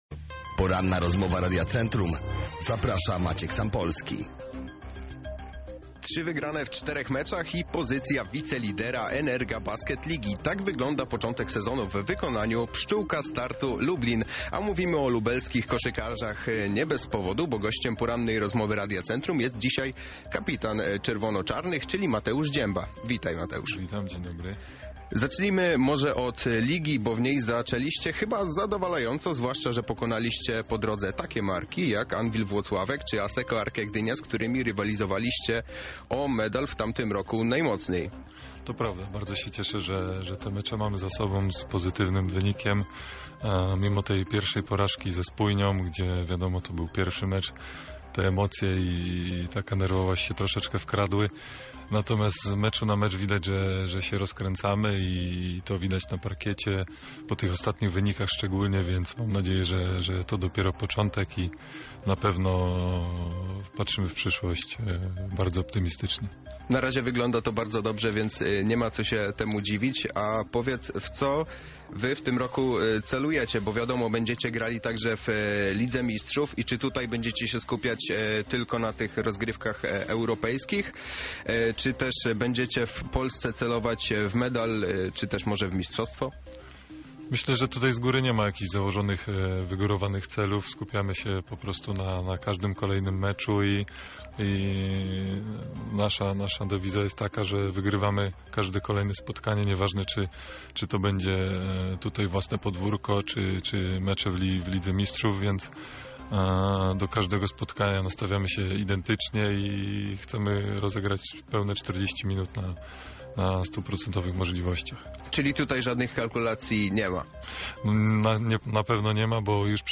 Cała rozmowa dostępna jest tutaj: